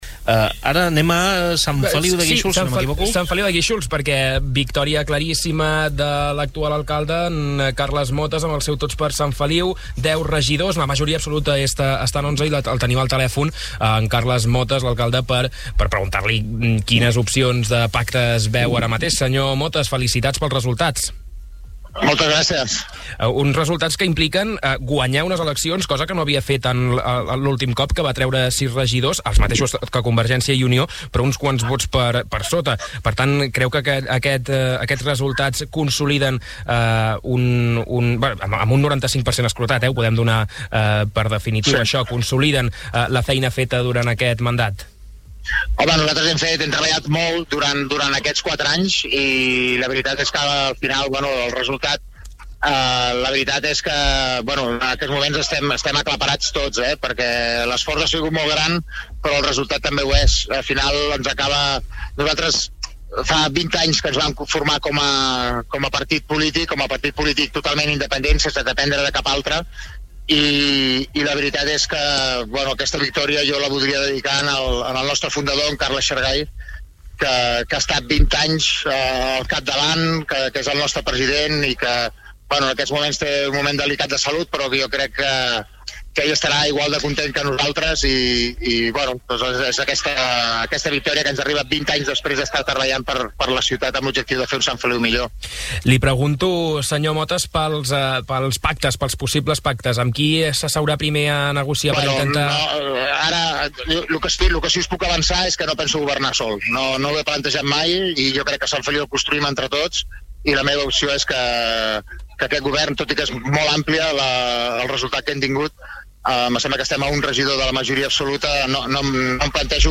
Ho va dir la mateixa nit electoral en un programa especial de Ràdio Capital